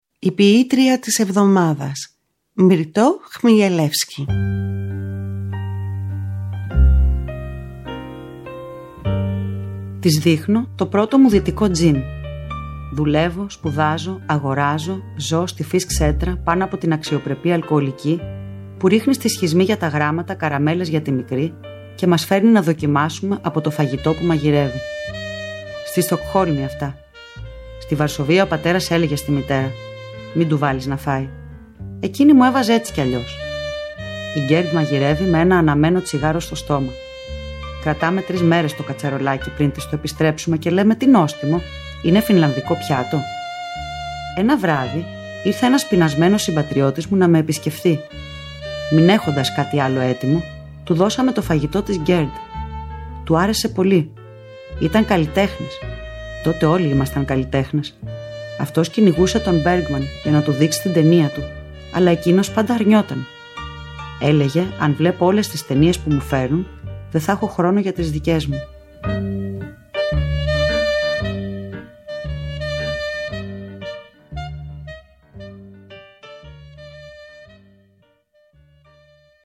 Κάθε εβδομάδα είναι αφιερωμένη σ’ έναν σύγχρονο Έλληνα ποιητή ή ποιήτρια, ενώ δεν απουσιάζουν οι ποιητές της Διασποράς. Οι ίδιοι οι ποιητές και οι ποιήτριες επιμελούνται τις ραδιοφωνικές ερμηνείες. Παράλληλα τα ποιήματα «ντύνονται» με πρωτότυπη μουσική, που συνθέτουν και παίζουν στο στούντιο της Ελληνικής Ραδιοφωνίας οι μουσικοί της Ορχήστρας της ΕΡΤ, καθώς και με μουσικά κομμάτια αγαπημένων δημιουργών.